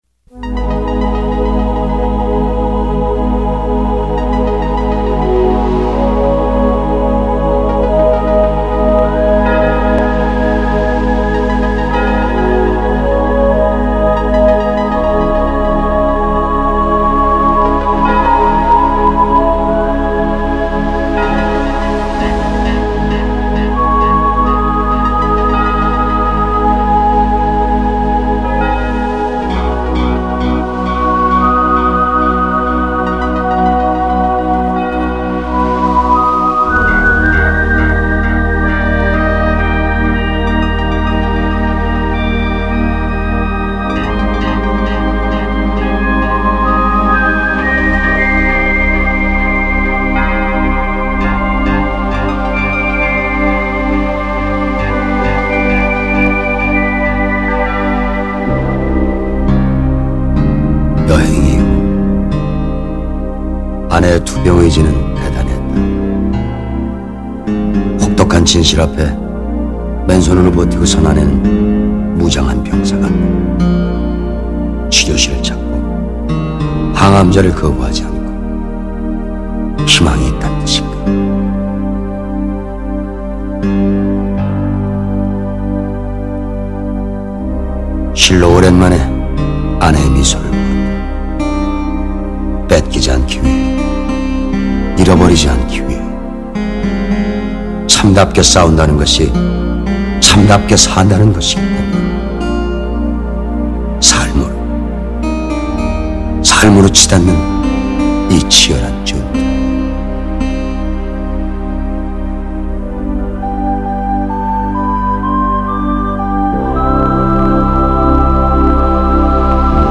Dialogue